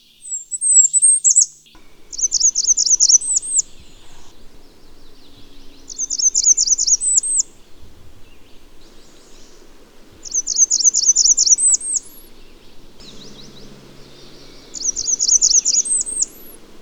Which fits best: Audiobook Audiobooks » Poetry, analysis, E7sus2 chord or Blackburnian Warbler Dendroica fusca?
Blackburnian Warbler Dendroica fusca